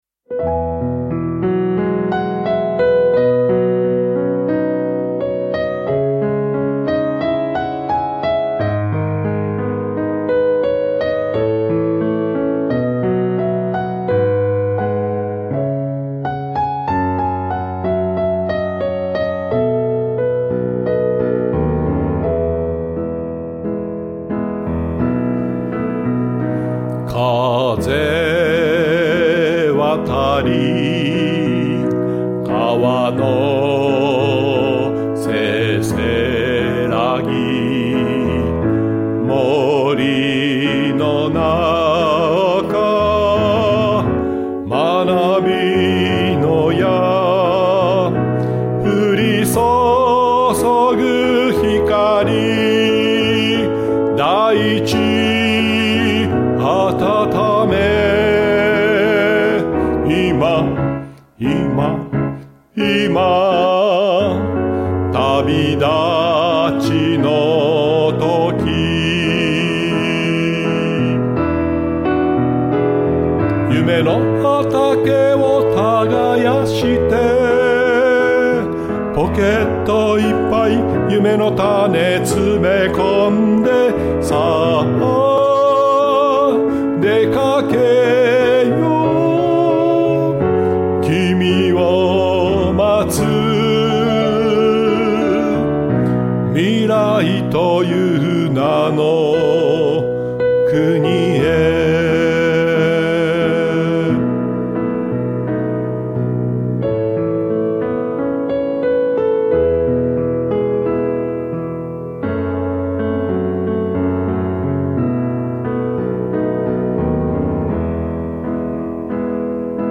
ライブ録音だった。